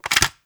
OPEN_CASE_02.wav